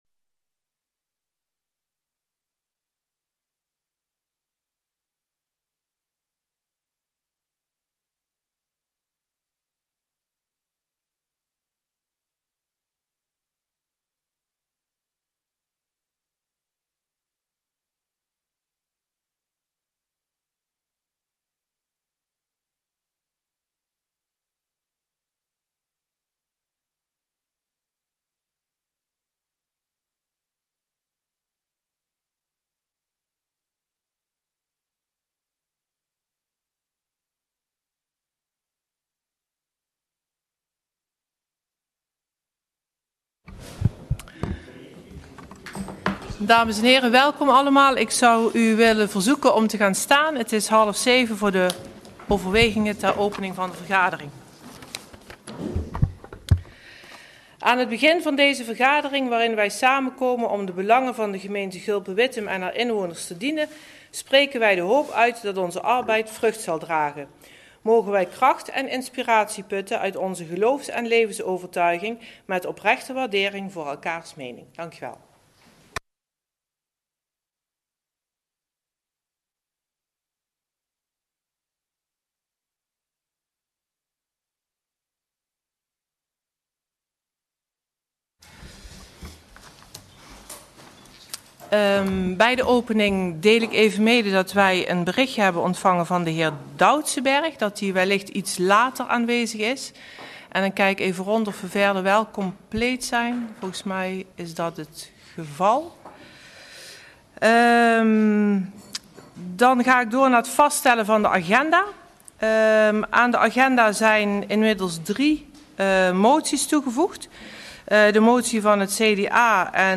Agenda GulpenWittem - Raadsvergadering donderdag 31 mei 2018 18:30 - 20:00 - iBabs Publieksportaal